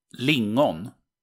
Ääntäminen
IPA: /ˈlɪŋˌɔn/ IPA: /ˈlɪ̀ŋɔn/